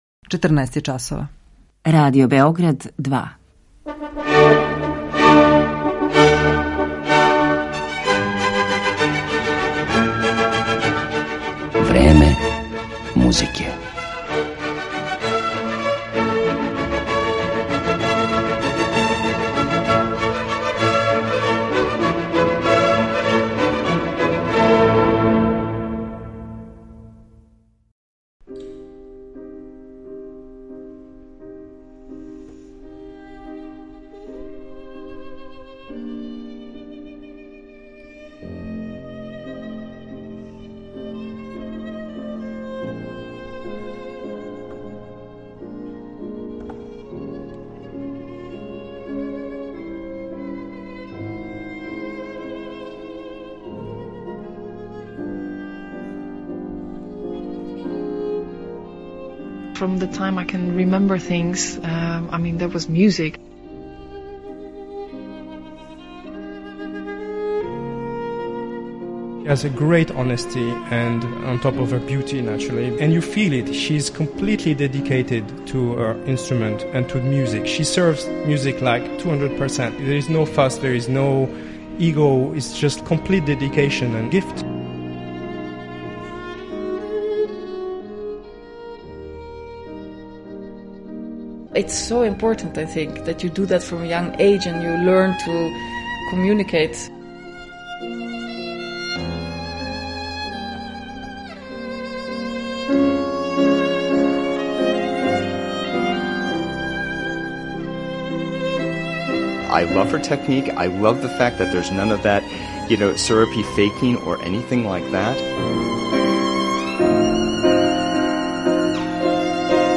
која осваја публику скоро романтичарском експресивношћу.